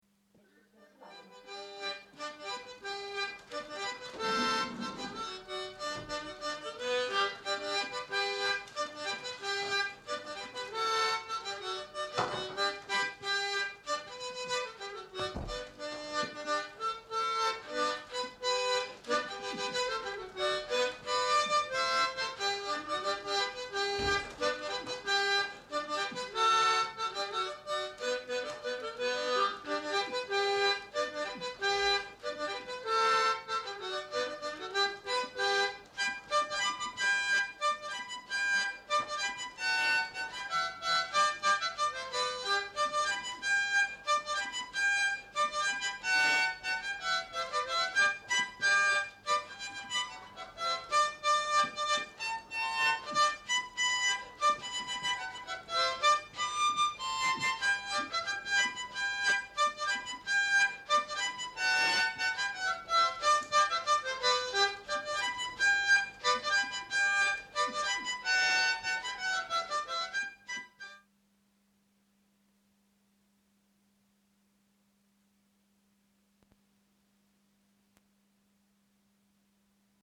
Scottish